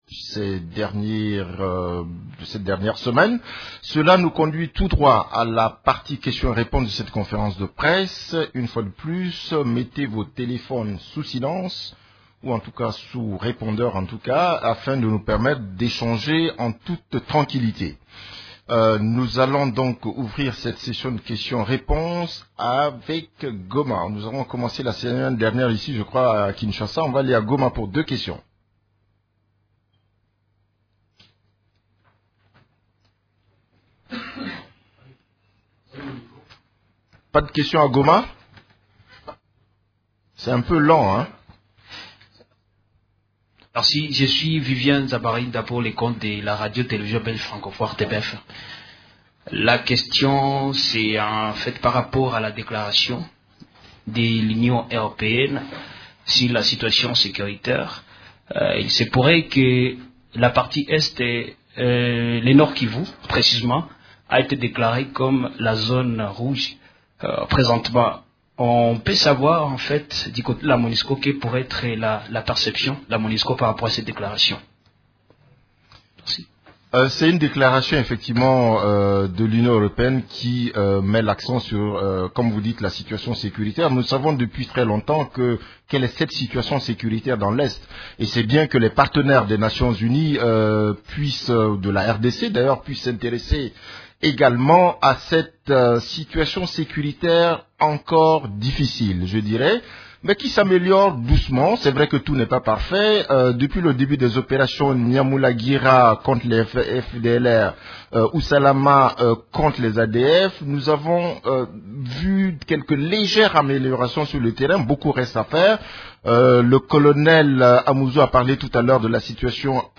Conférence de presse du 29 juin 2016
La conférence de presse hebdomadaire des Nations unies du mercredi 29 juin à Kinshasa a porté sur les activités des composantes de la MONUSCO, les activités de l’équipe-pays et la situation militaire.